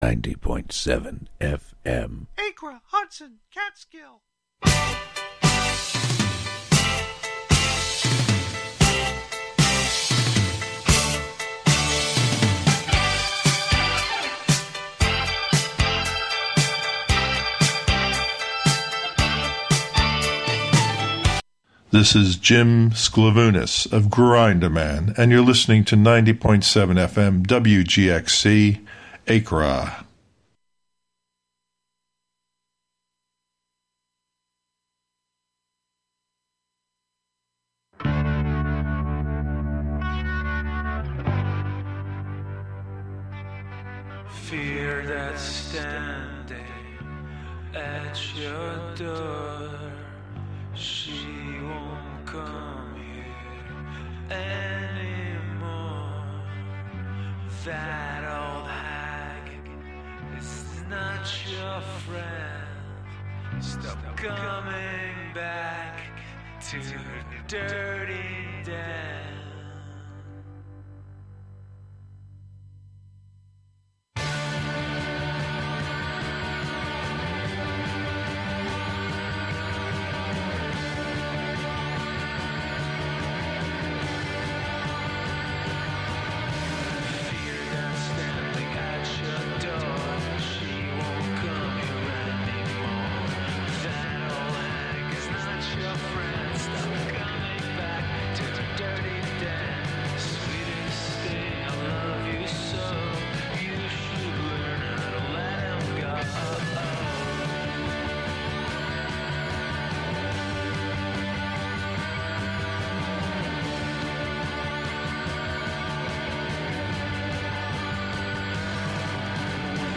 Each broadcast features candid conversations with unique guests. Join us in celebrating the richness of artistic expression in our community and beyond.